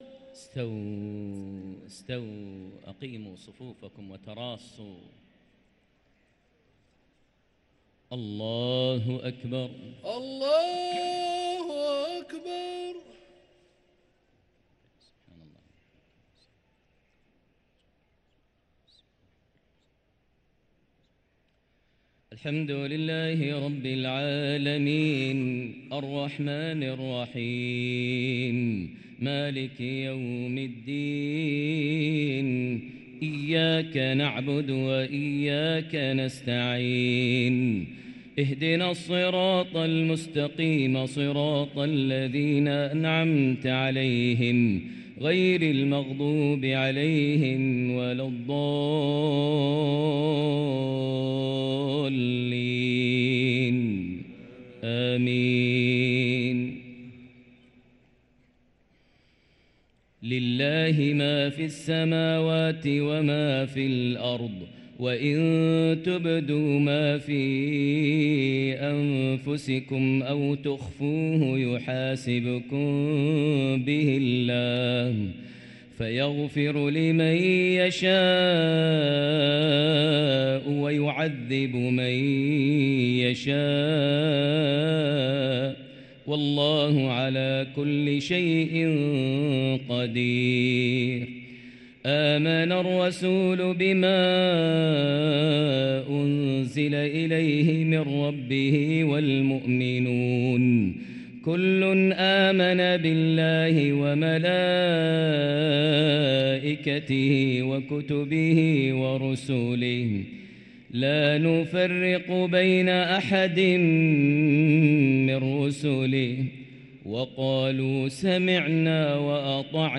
صلاة العشاء للقارئ ماهر المعيقلي 28 رمضان 1444 هـ
تِلَاوَات الْحَرَمَيْن .